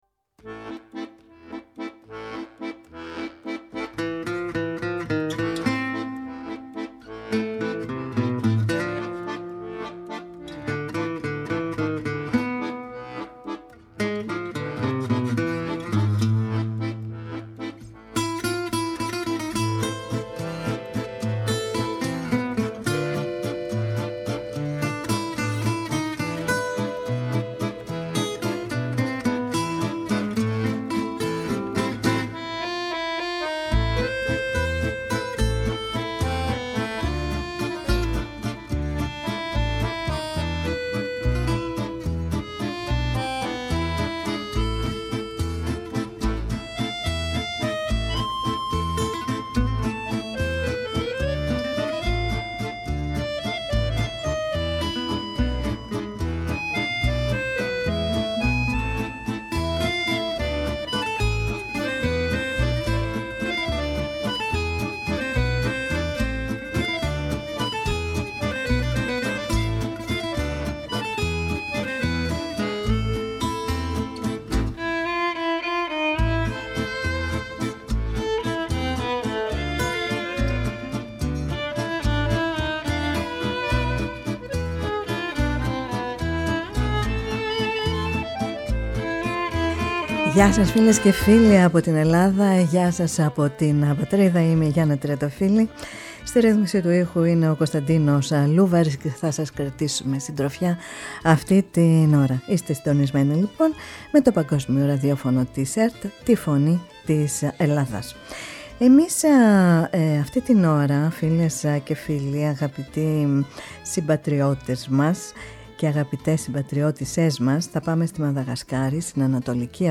φιλοξένησε στη «Φωνή της Ελλάδας» τον Επίσκοπο της Ιεράς Επισκοπής Τολιάρας Νοτίου Μαδαγασκάρης, πατέρα Πρόδρομο.